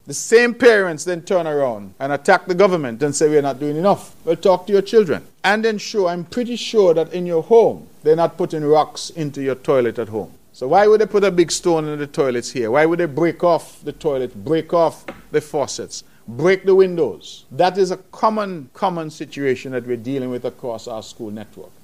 The voice of Nevis’ Premier, Dr. Hon. Mark Brantley as he spoke during his monthly press conference last Thursday.